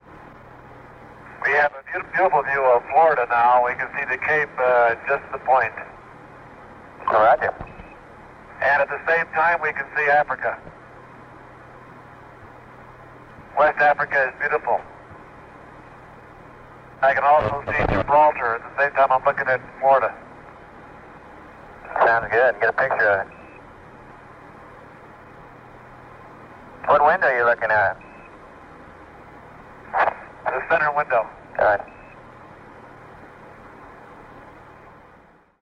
Audio recorded at Honeysuckle Creek